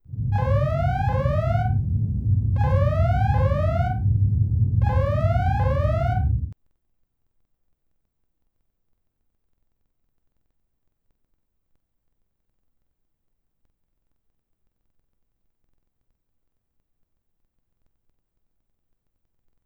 RedAlert.wav